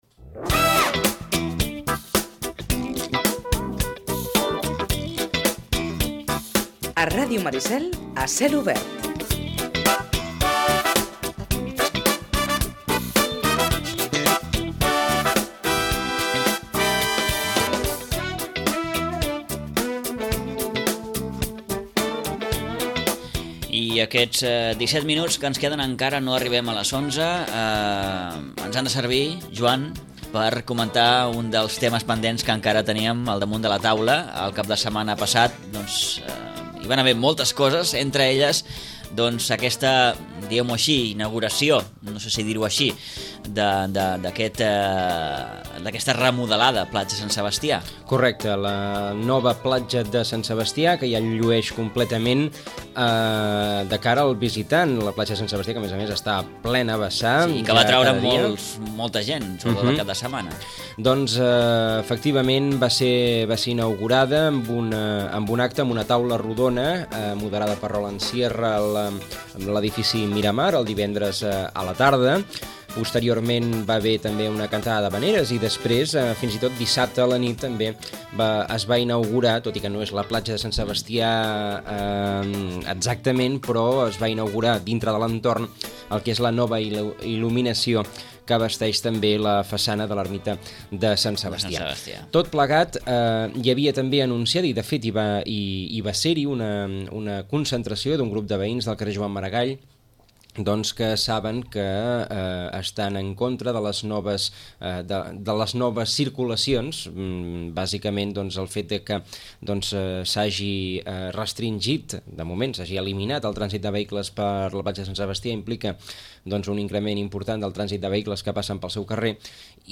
Una festa i una xerrada han servit per inaugurar la remodelació del passeig de la platja de Sant Sebastià. El regidor adjunt de projectes estratègics, Marc Quero, explica en roda de premsa en què ha consistit l’actuació.